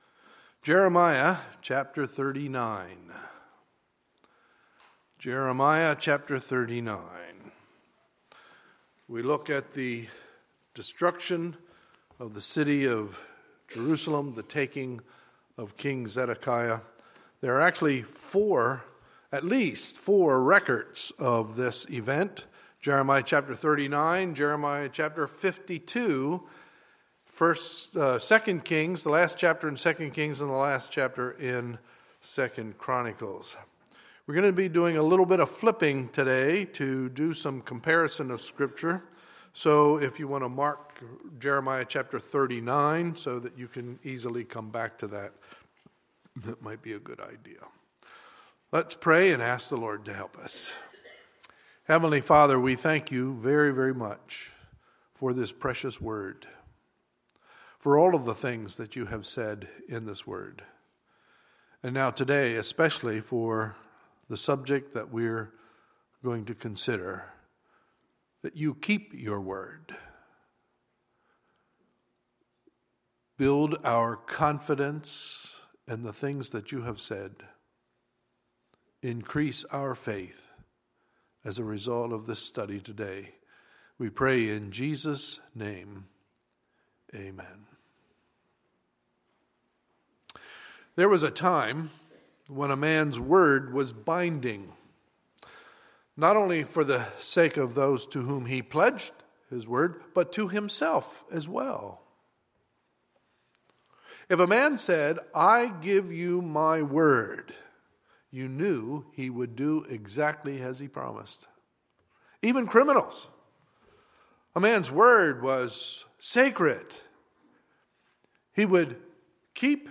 Sunday, November 15, 2015 – Sunday Morning Service
Sermons